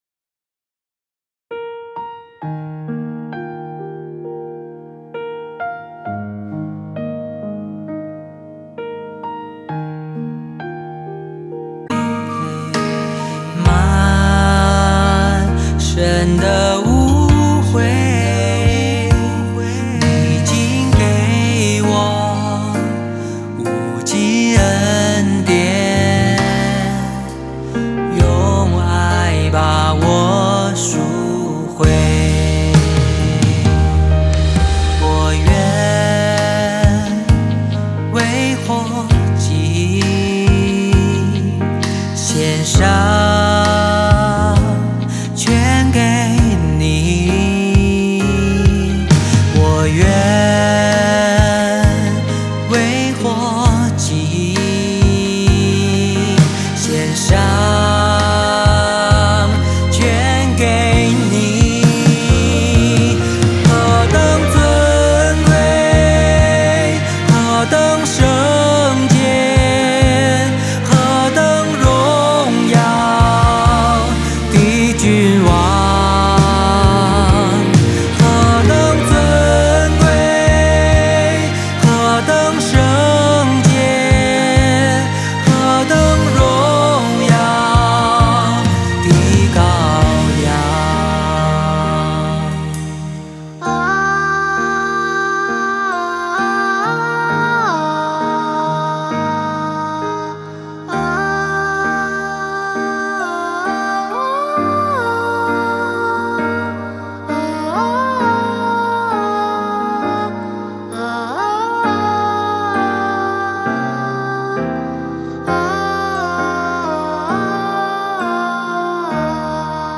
降E調 | 66bpm